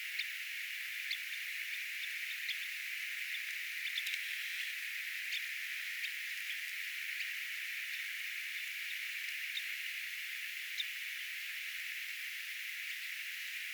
pulmussirrinuoren lentoääntelyä
pulmussirrinuoren_lentoaantelya.mp3